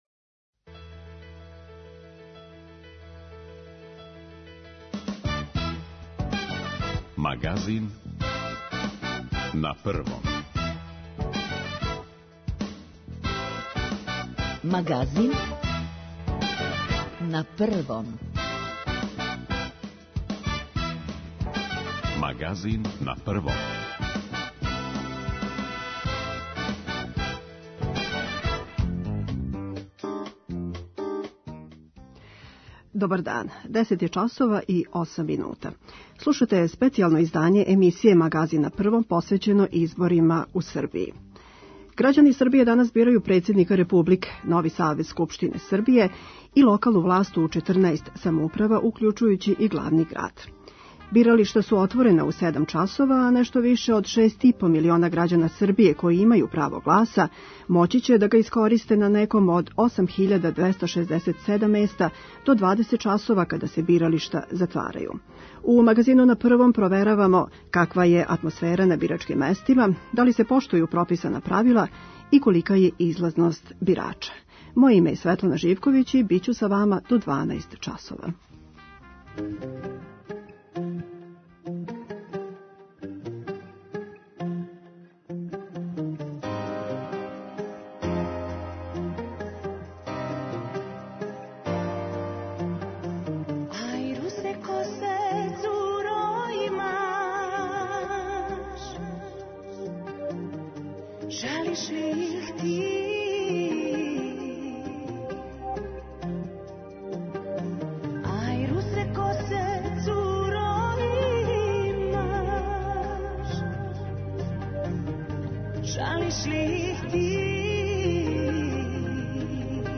У Магазину на првом проверавамо каква је атмосфера на бирачким местима, да ли се поштују прописана правила и колика је излазност бирача. Репортери Радио Београда 1 налазе се на бирачким местима, у Републичкој изборној комисији и невладиним организацијама које прате регуларност изборног процеса.